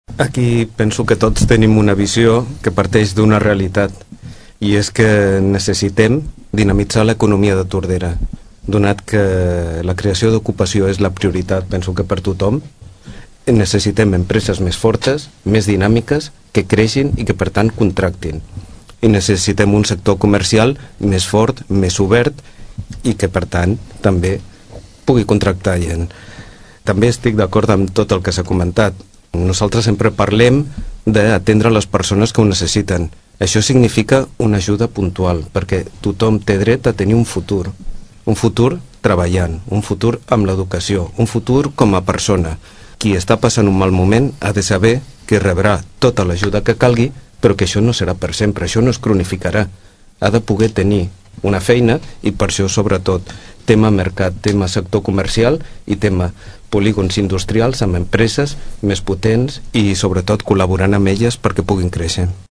La tertúlia de polítics se celebra cada mes, la següent setmana després del plenari municipal.